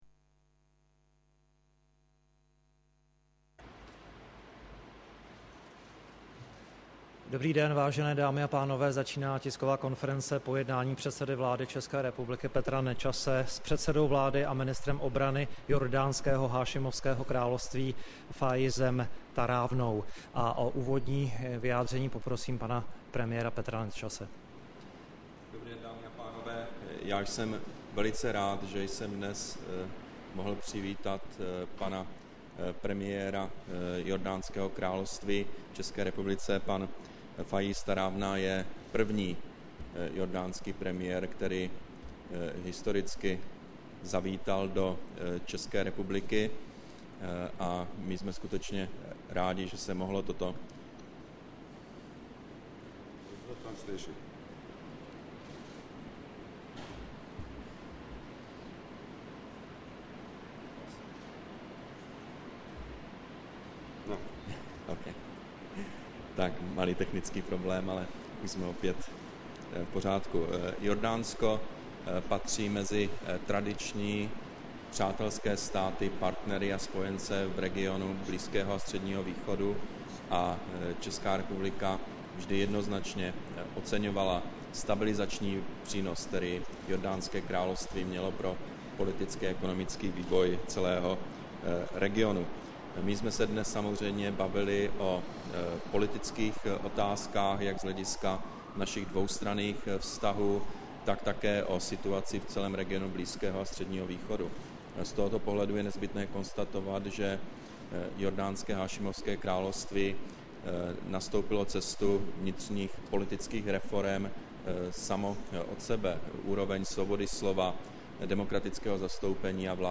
Tisková konference předsedy vlády ČR Petra Nečase a předsedy vlády Jordánska Fajíze Tarawná, 16. července 2012